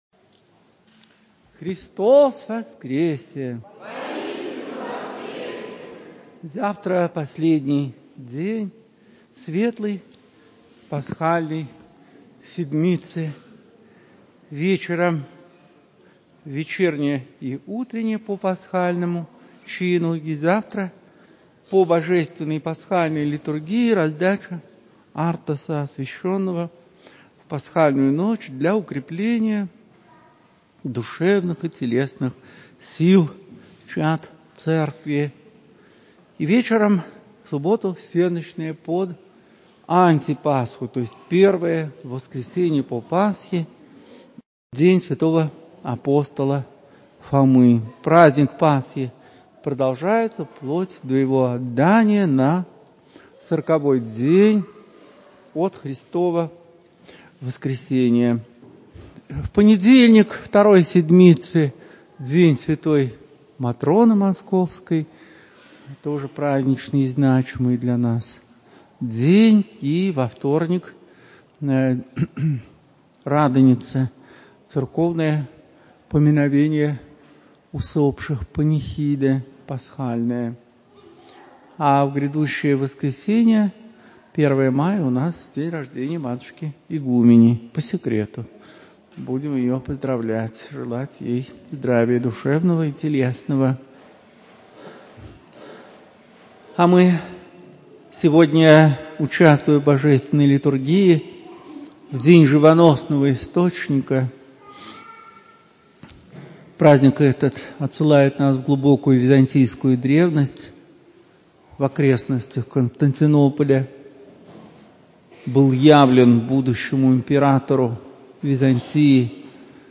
В храме Всех Святых ставропигиального женского Алексеевского монастыря. На литургии, 29 апреля 2022.